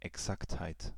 Ääntäminen
Synonyymit Genauigkeit Ääntäminen Tuntematon aksentti: IPA: /ɛˈksakthaɪ̯t/ Haettu sana löytyi näillä lähdekielillä: saksa Käännöksiä ei löytynyt valitulle kohdekielelle.